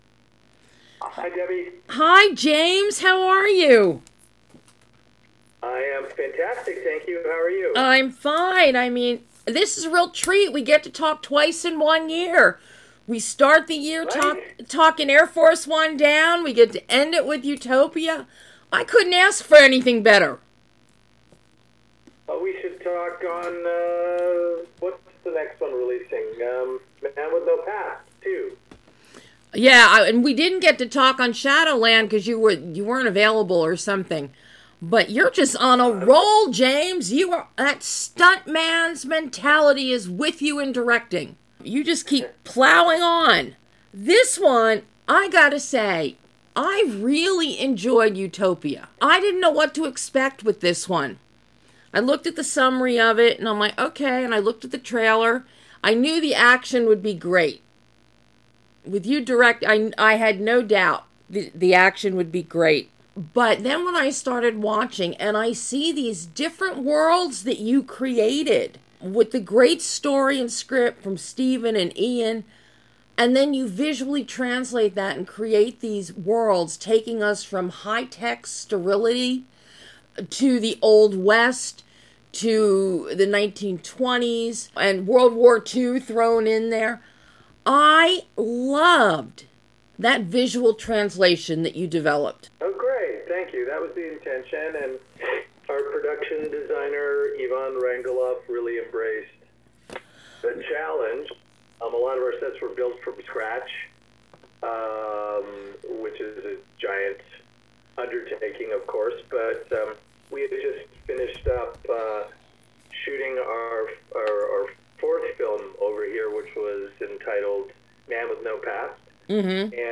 UTOPIA - Exclusive Interview